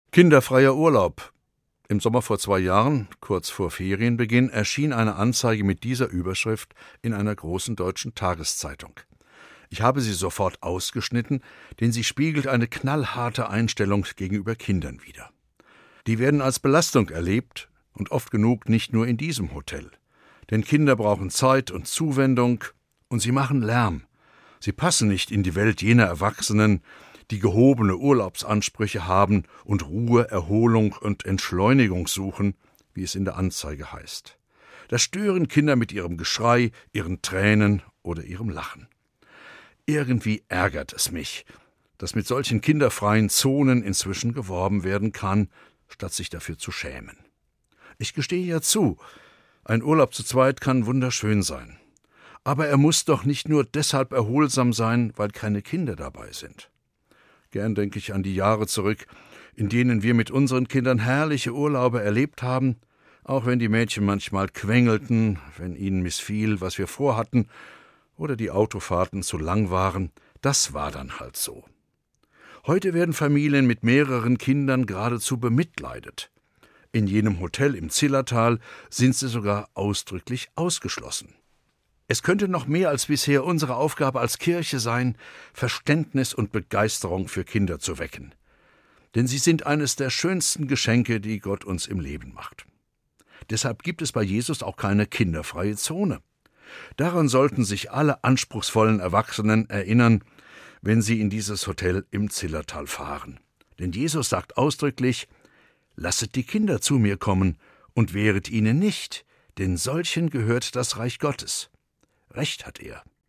Ein Beitrag von Prof. Dr. Martin Hein, Bischof der Evangelischen Kirche von Kurhessen-Waldeck, Kassel